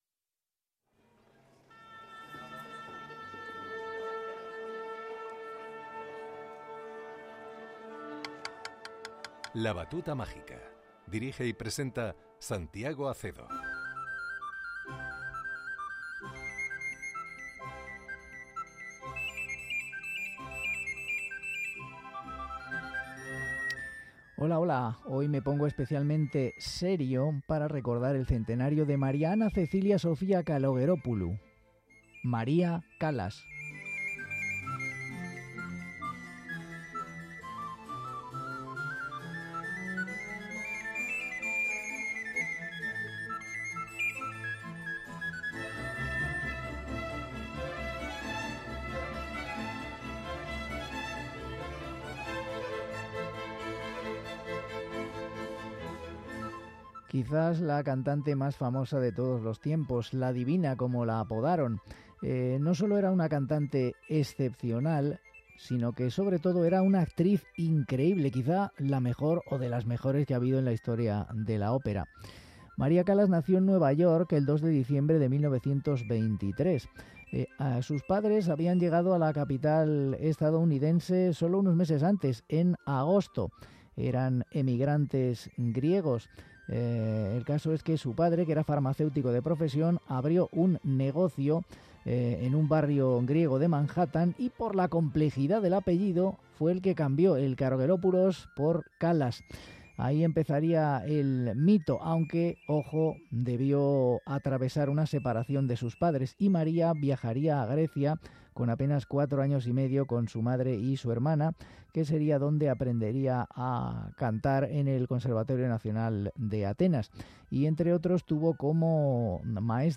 arias